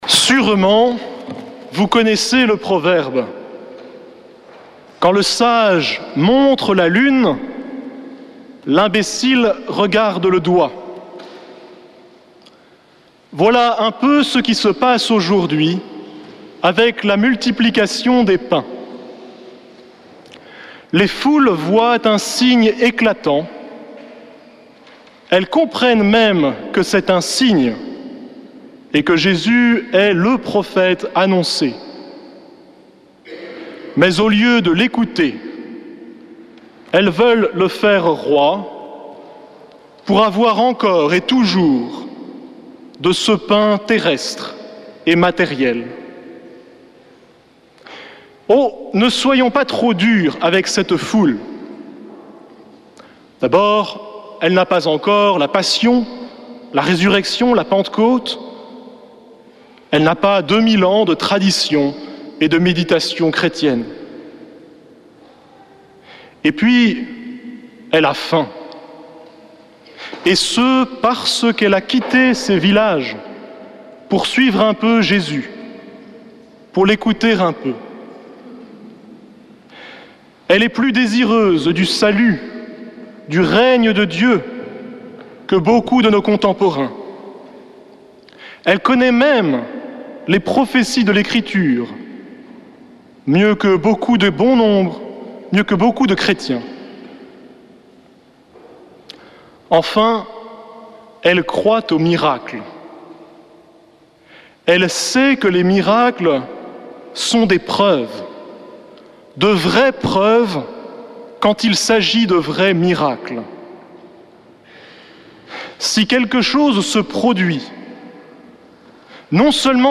Messe depuis le couvent des Dominicains de Toulouse
Homélie du 28 juillet